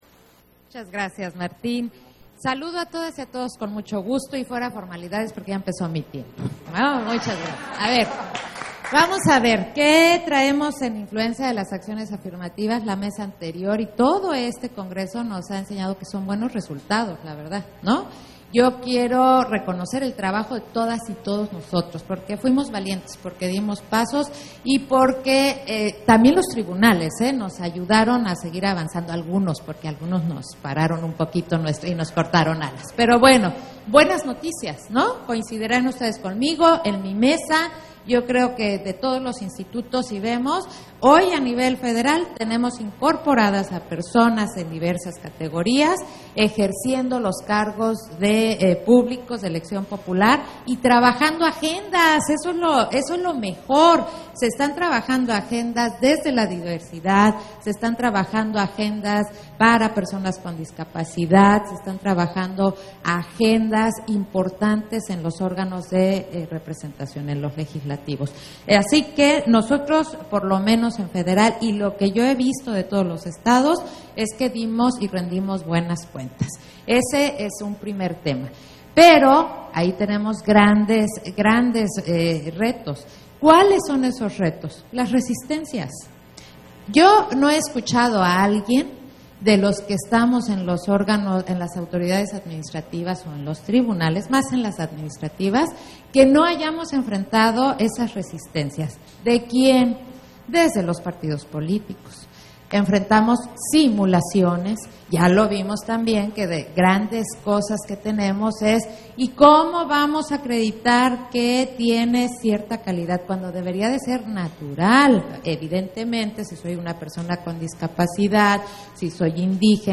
Intervención de Claudia Zavala, en la mesa, Influencia de las acciones afirmativas en los avances y retos de la construcción de la ciudadanía, Congreso Nacional de Igualdad de Género e Inclusión